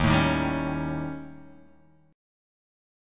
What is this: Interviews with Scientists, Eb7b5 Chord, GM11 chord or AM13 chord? Eb7b5 Chord